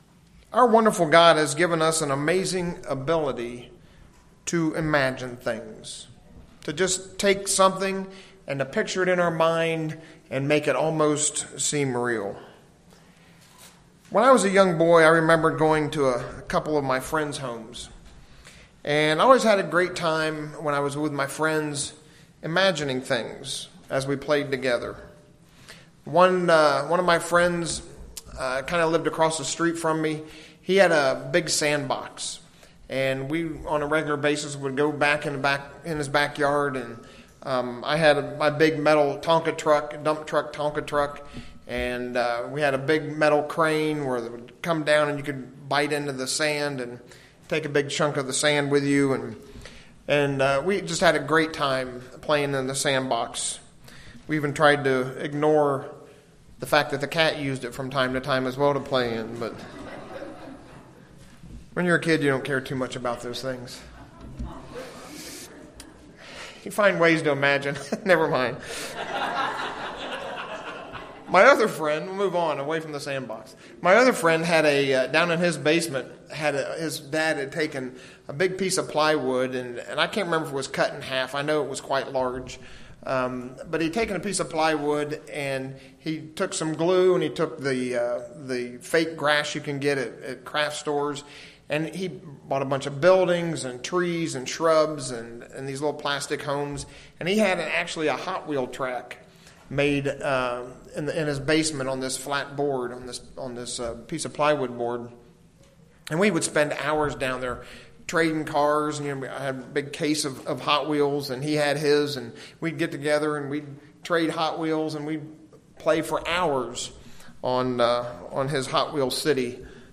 God has given us an amazing ability to imagine things. The purpose of this sermon is to have you use your imagination to put yourself into the events that lead up to the return of Christ at the last trumpet.